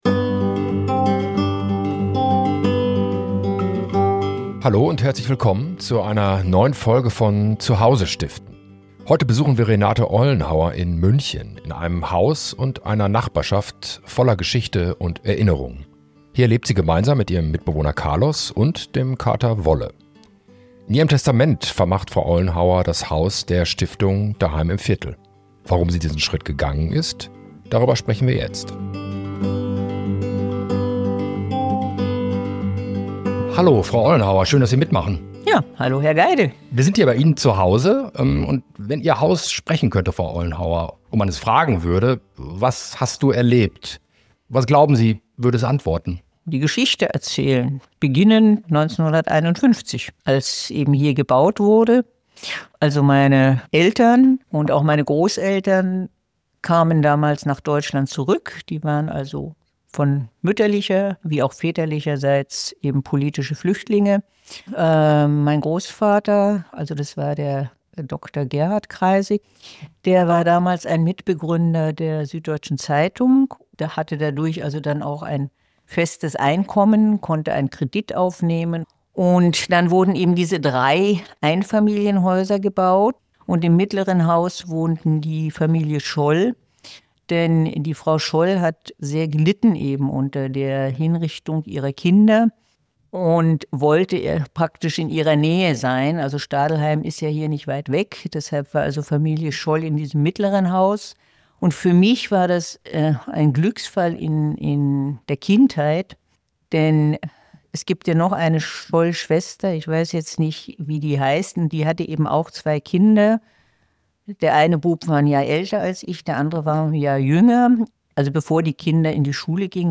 Warum sie sich gegen den Verkauf entschieden hat, was ihr am Erhalt von Garten, Gemeinschaft und sozialverträglichem Wohnen wichtig ist und weshalb sie nun mit einem guten Gefühl „loslassen“ kann – ein sehr persönliches Gespräch über Erinnerung, Verantwortung und Zukunft.